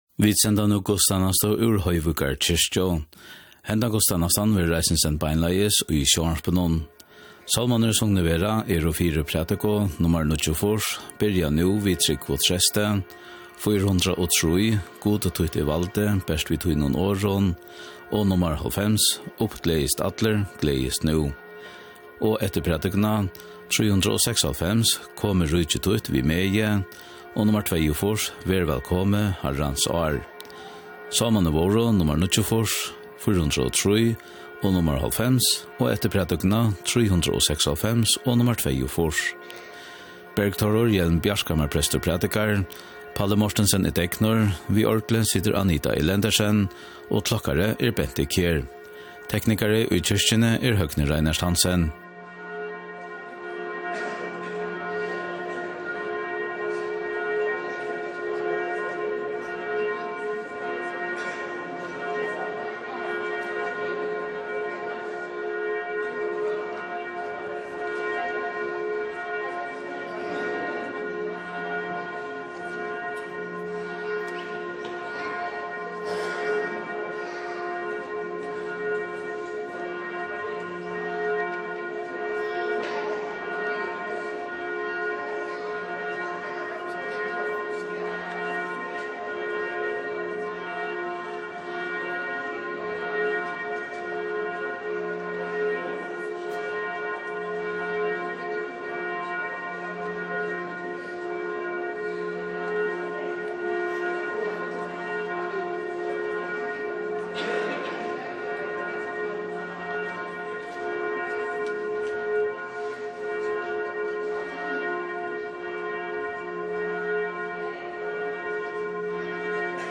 Guðstænasta í Hoyvíkar kirkju